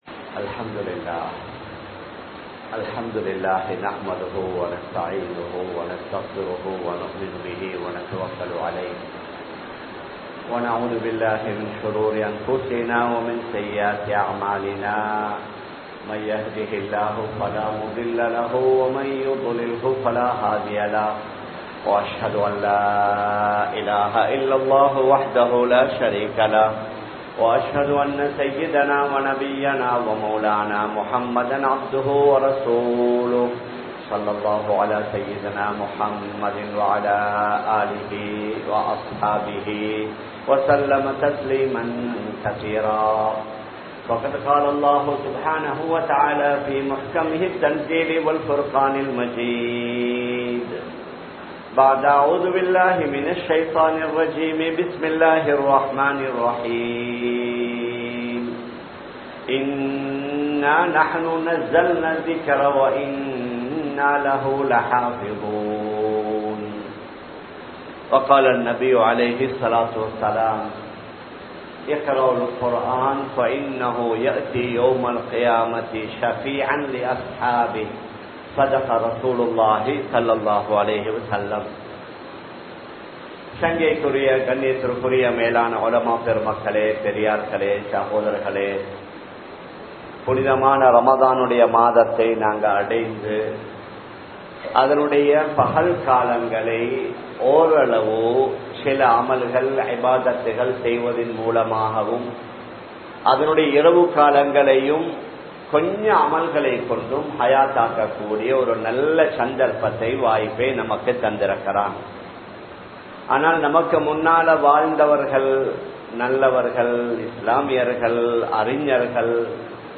அல்குர்ஆன் மீது எமது கடமை | Audio Bayans | All Ceylon Muslim Youth Community | Addalaichenai